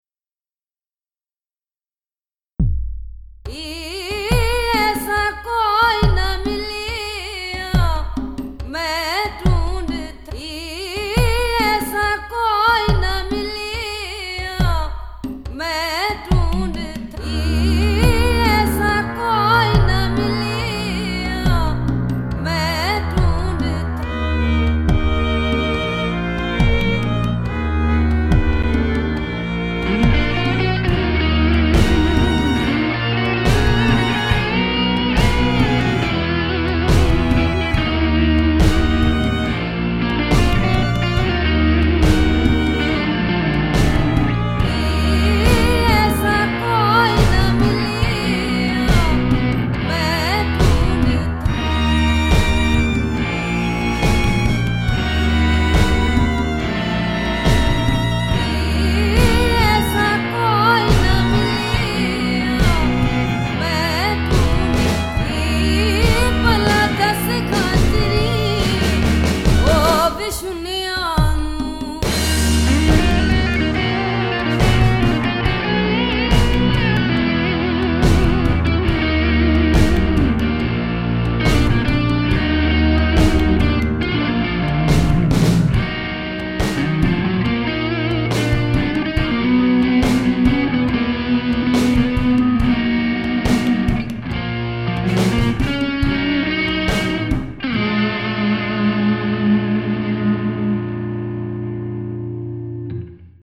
This piece was built around a vocal sample that I’ve had for so long I forgot where it came from but I really like it.
I don’t even know what the language is, but it sounds like something from the middle east.
To me the piece sounds like it could be the soundtrack to something that pops into your head while you’re not quite awake but not quite conscious either.
So this song contains the vocal sample of forgotten origin, a couple of real guitar tracks through a digitech GXN3 and midi programmed drums, percussion, string bass and violins.